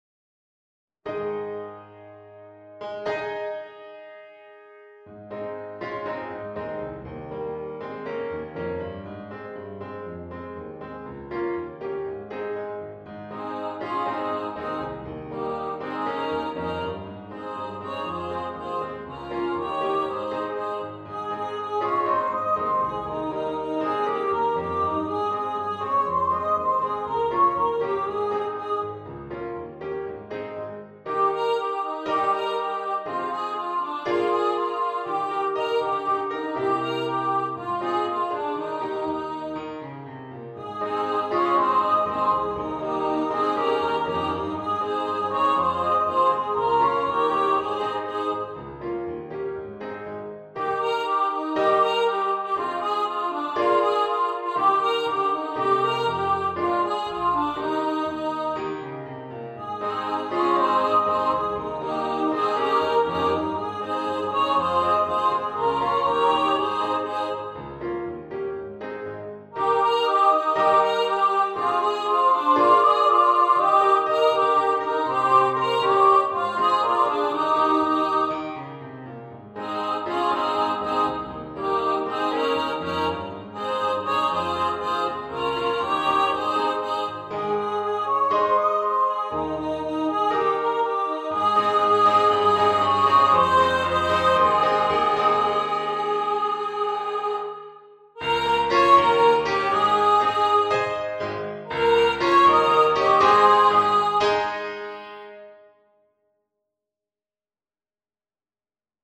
for upper voice choir and piano
folk song
Choir - Upper voices